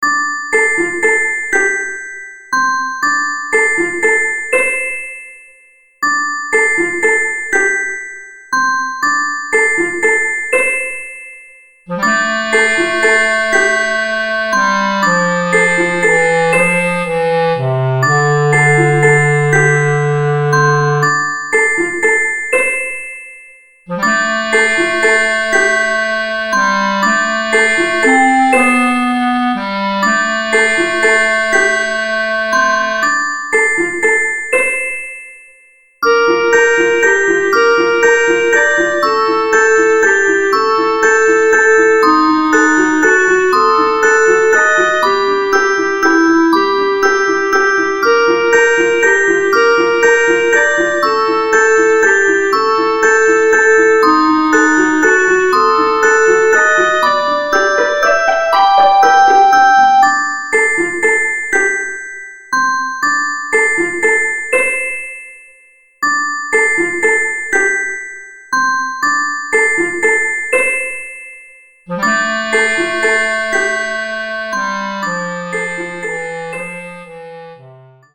～～不思議な曲～～